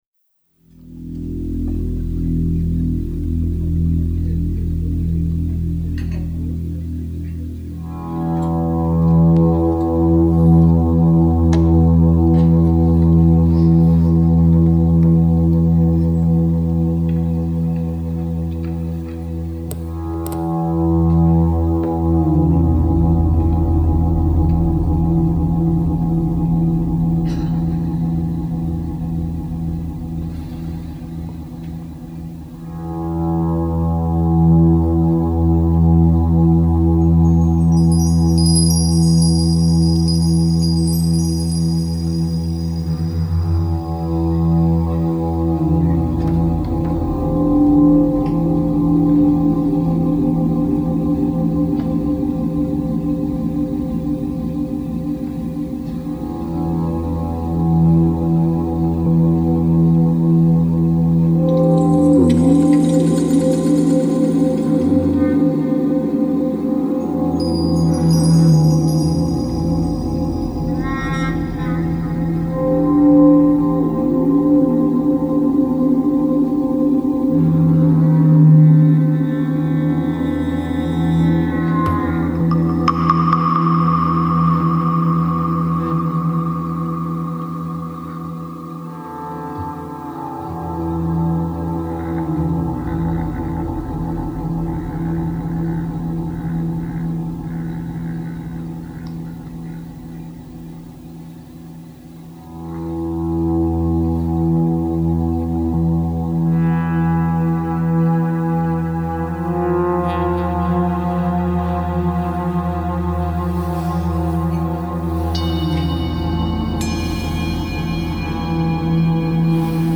Live at the FlynnSpace 2/6/04